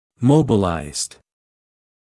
[‘məubəlaɪzd][‘моубэлайзд]мобилизированный; подвижный